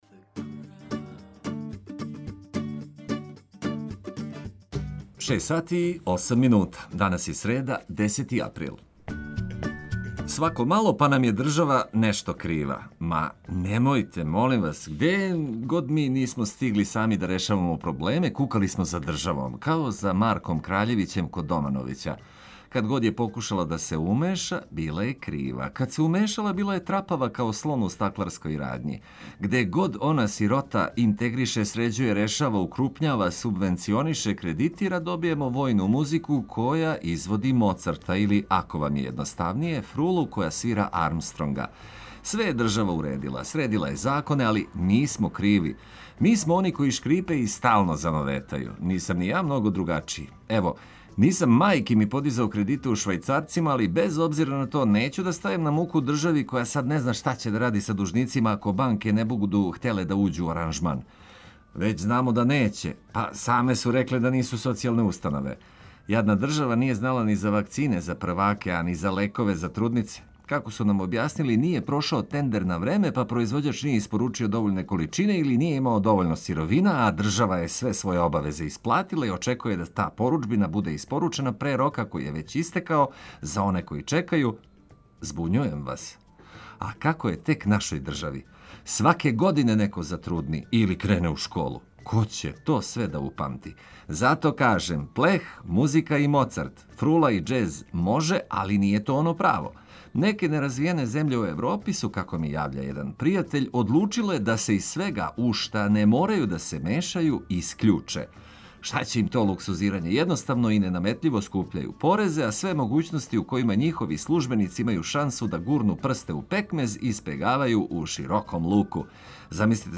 Обезбедите себи пријатно буђење уз много музике и прегршт корисних информација.